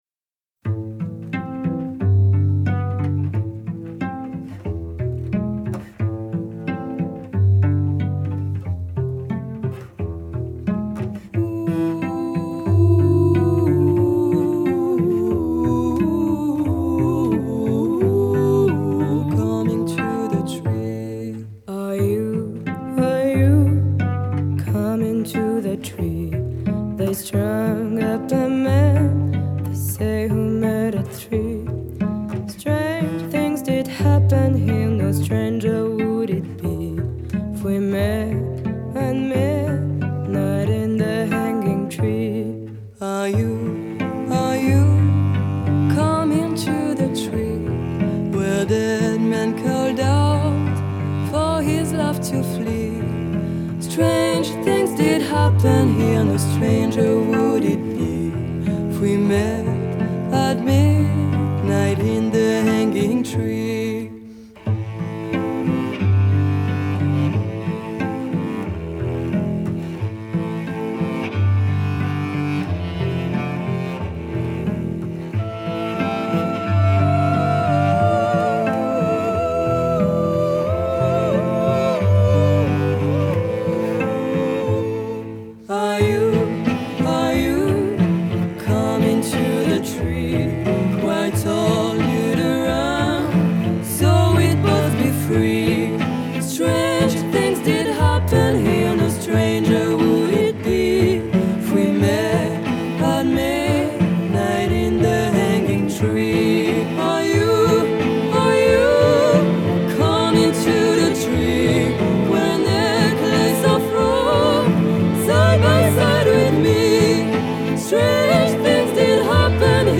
Строго, без излишеств – вокал, виолончель и барабан.
Genre: French Music, Pop